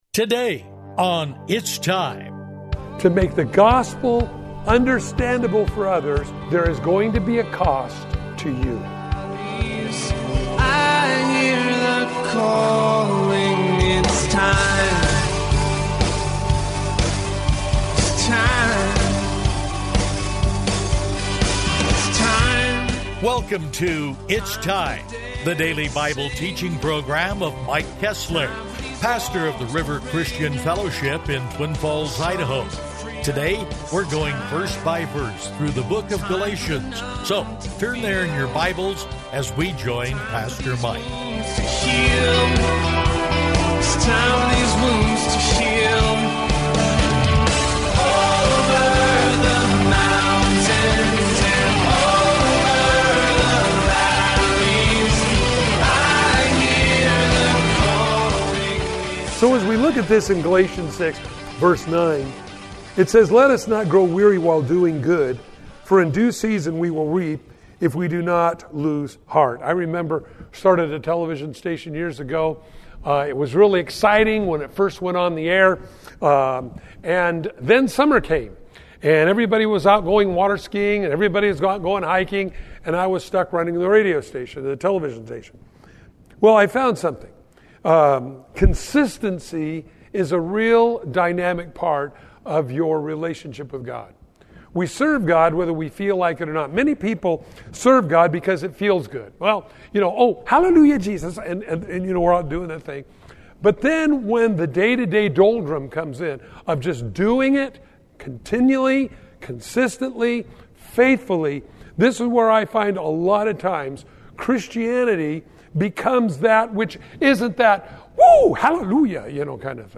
Bible-teaching radio ministry